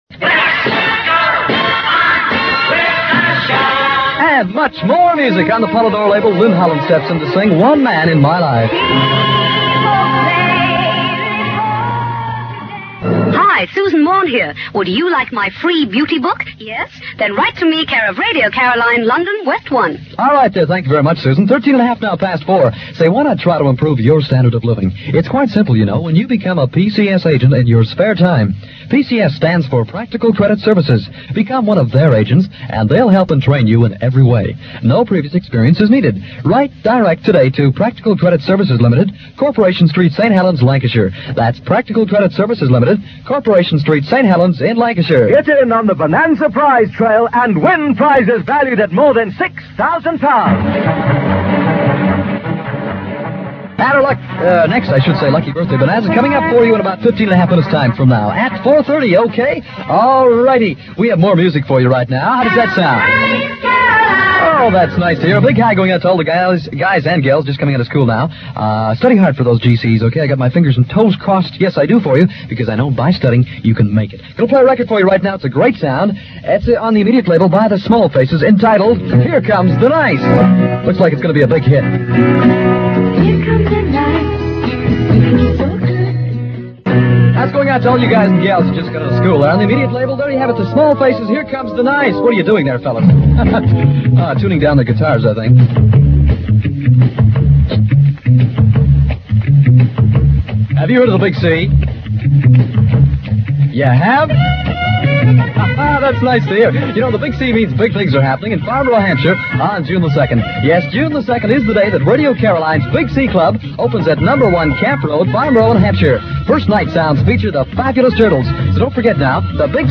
3-6pm show on Radio Caroline North